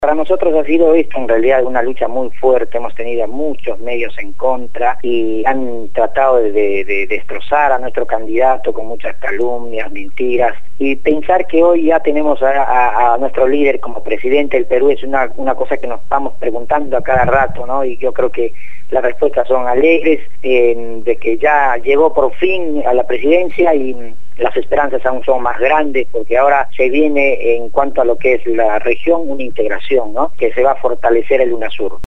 habló sobre la victoria de Ollanta Humala en el programa «Desde el Barrio» (lunes a viernes de 9 a 12 horas) por Radio Gráfica FM 89.3